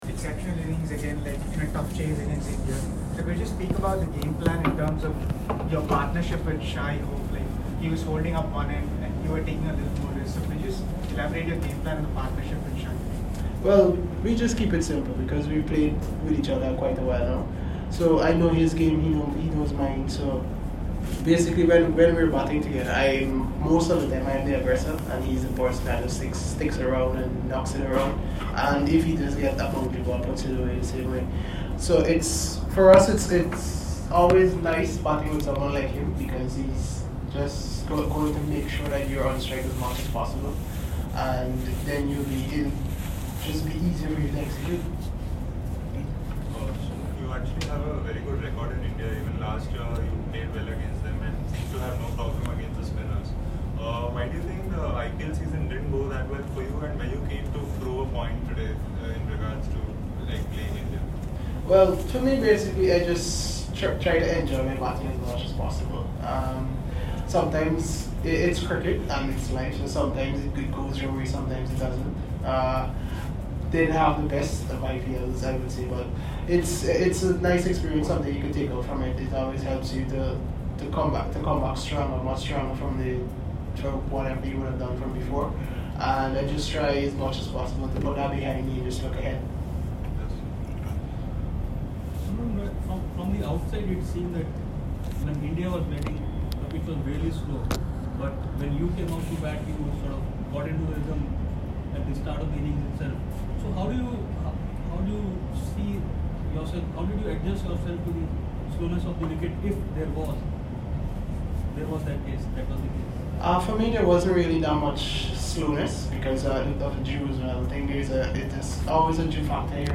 Shimron Hetmyer spoke to the media after the West Indies beat India by eight wickets
West Indies batsman Shimron Hetmyer spoke to members of the media after the first One-Day International against India at the M.A. Chidambaram Stadium.